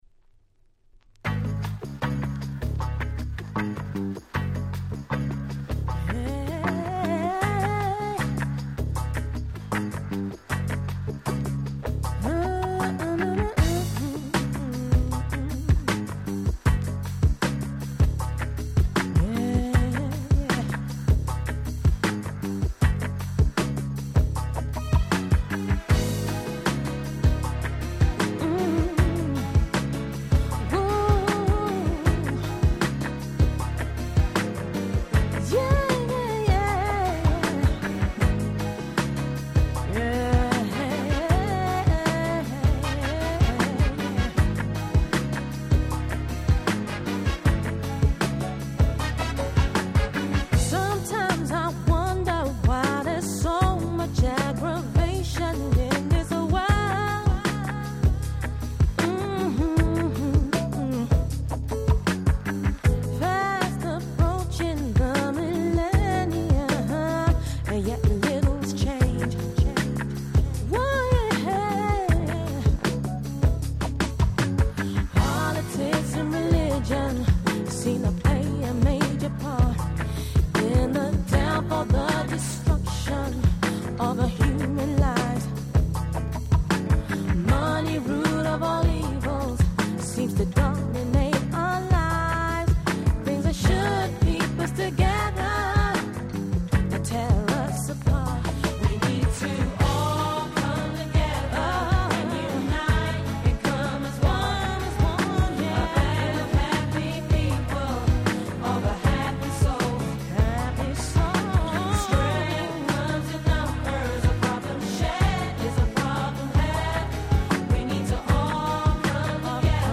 頭から尻尾の先までNiceなUK Soulがてんこ盛り！